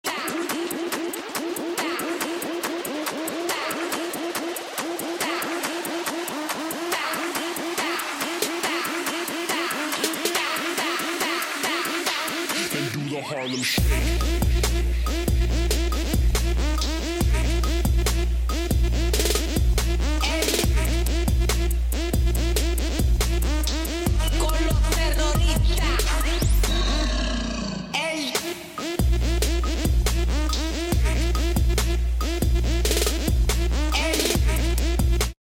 trap banger